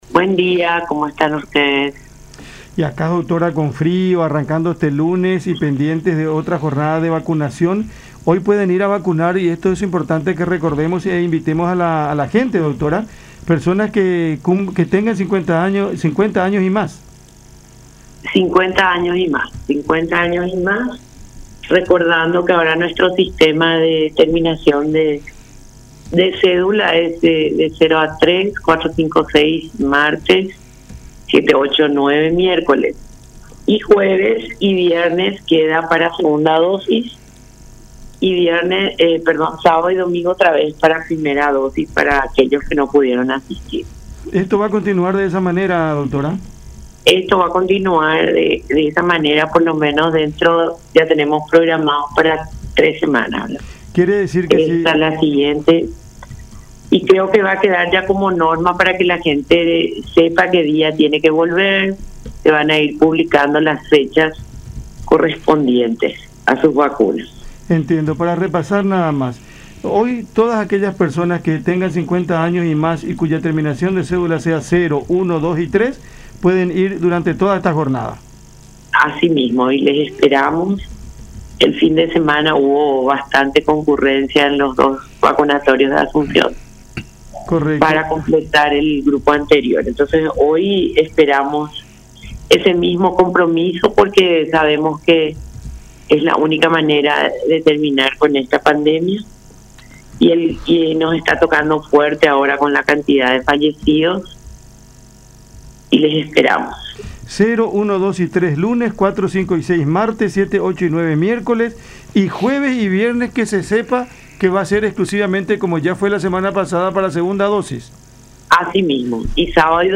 en conversación con Cada Mañana por La Unión.